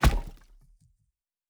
Stone 03.wav